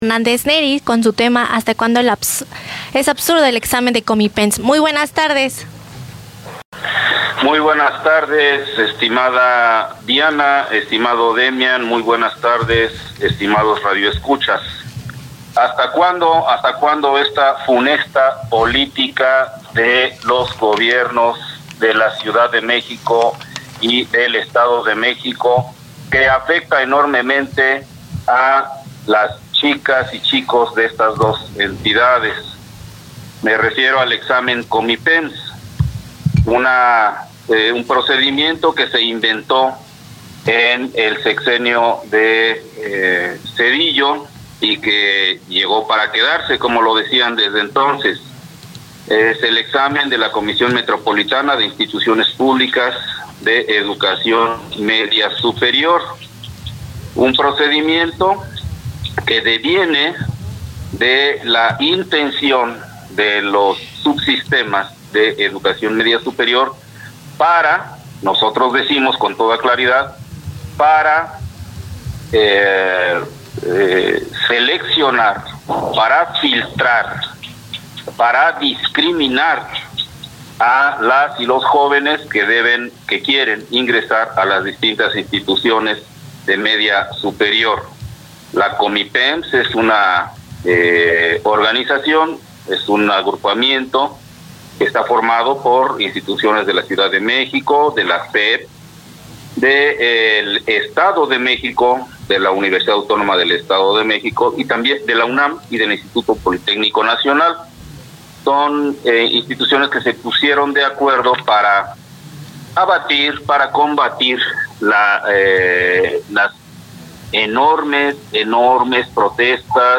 Participación en Neza Radio. 27 de Marzo 2024 Publicaciones Relacionadas: 27 de Julio.